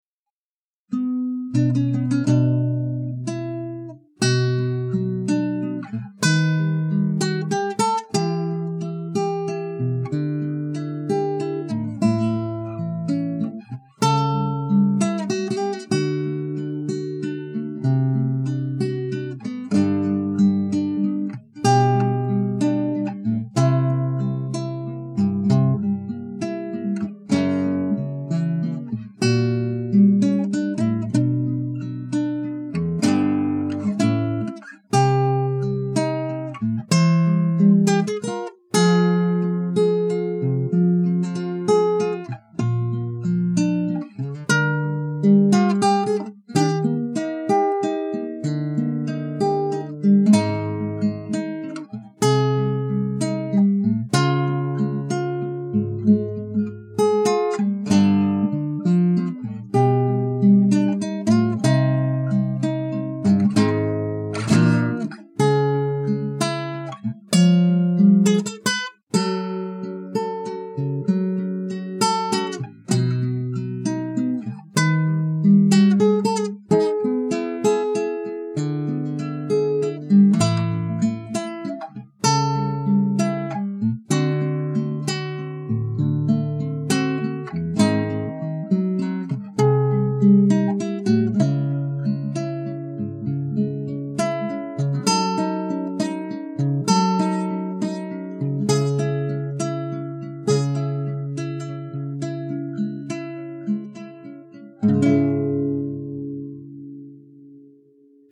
до-диез минор